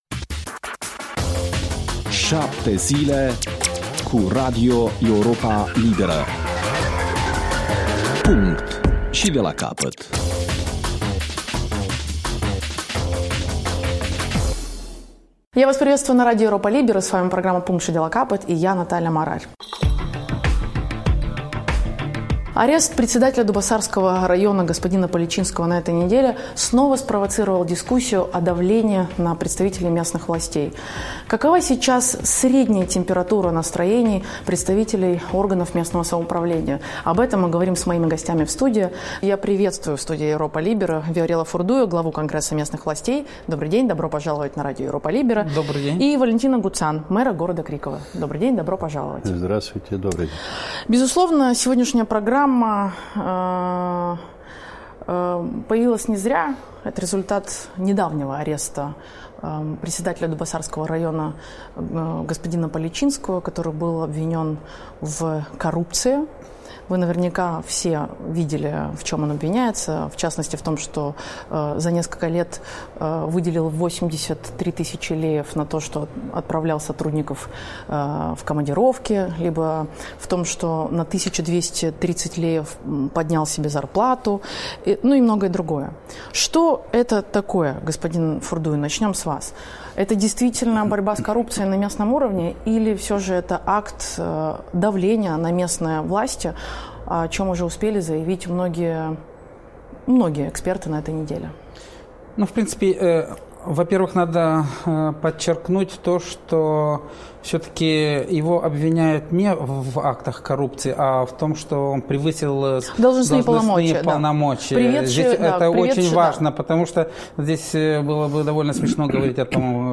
Emisiune în limba rusă.